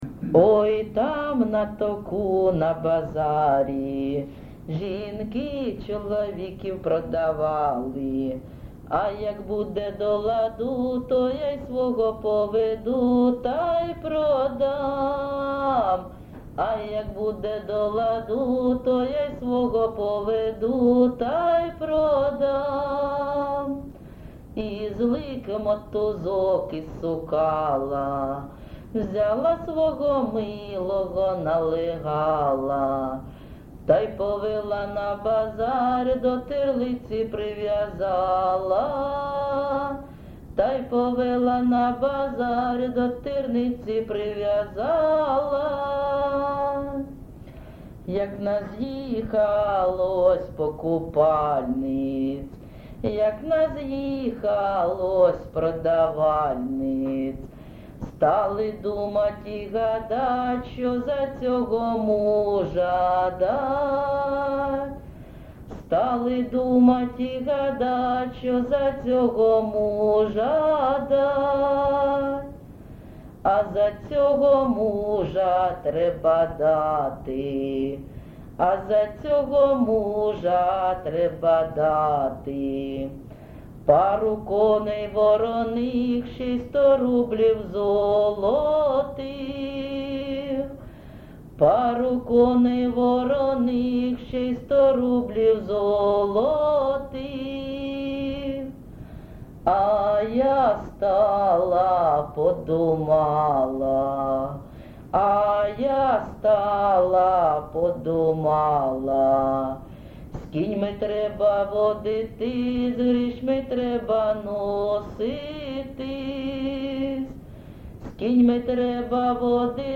ЖанрЖартівливі
Місце записус-ще Олексієво-Дружківка, Краматорський район, Донецька обл., Україна, Слобожанщина